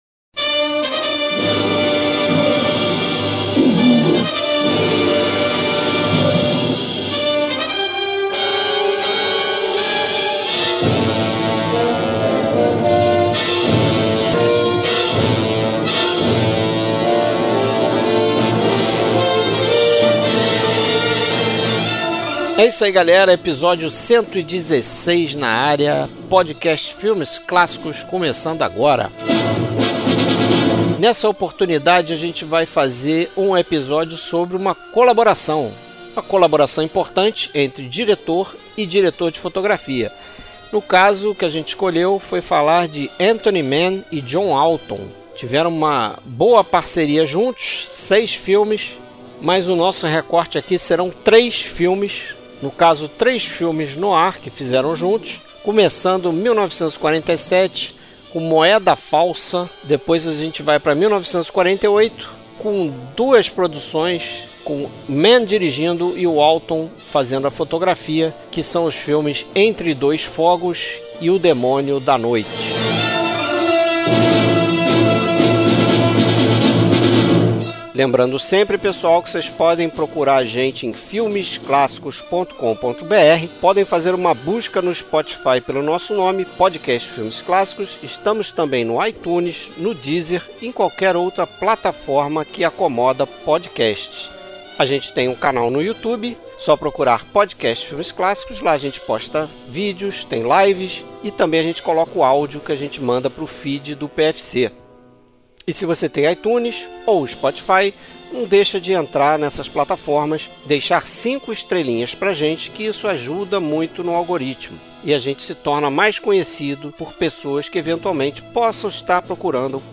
Trilha Sonora: trilha sonora de compositores que trabalharam nos filmes debatidos.